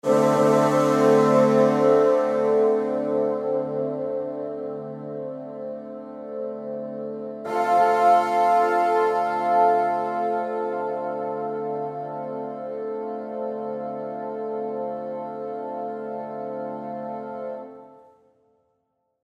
Oder mit Repetitionen bei den Sustain-Decrescendi, hier am Beispiel der Fagotte:
Diese Decrescendo-Samples sind übrigens ebenso wie die Sustains praktisch unhörbar geloopt – und das ist auch Anno 2016 bemerkenswert.